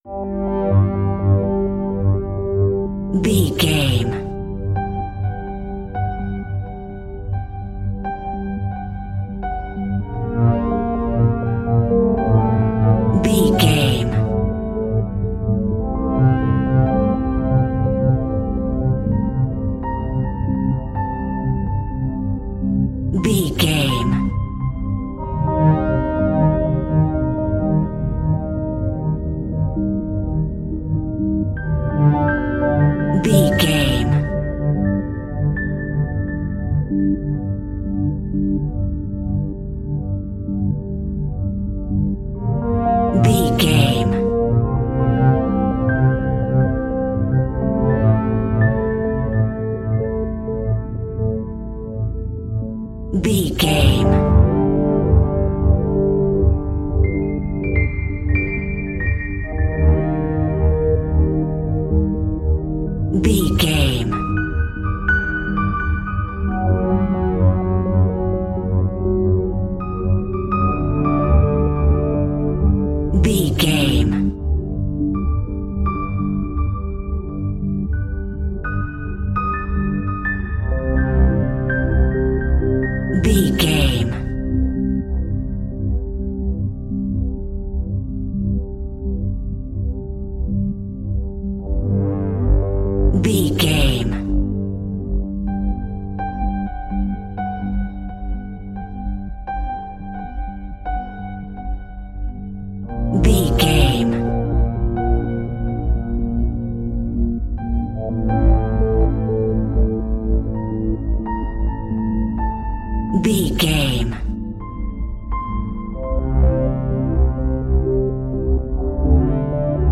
Aeolian/Minor
Slow
ominous
haunting
eerie
brass
piano
synthesiser
instrumentals
horror music